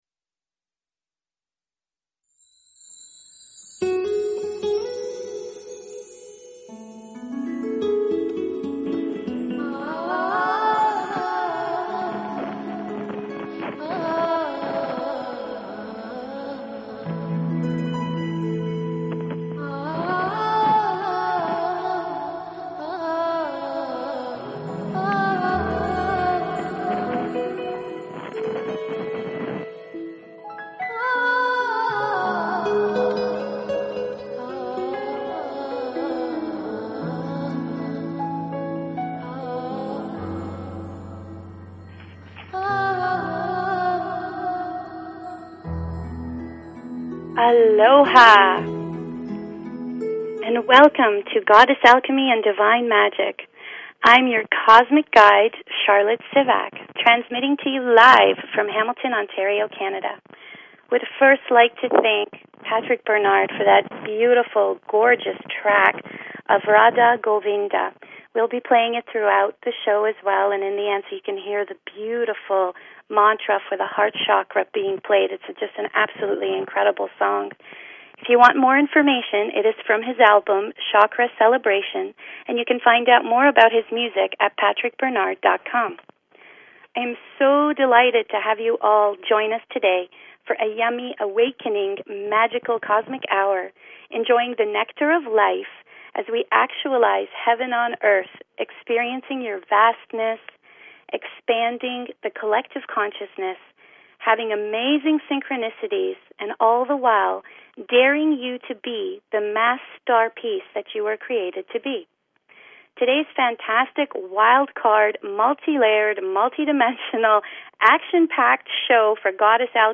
Show 8 of Goddess Alchemy and Divine Magic on BBS Radio!